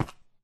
Sound / Minecraft / step / stone3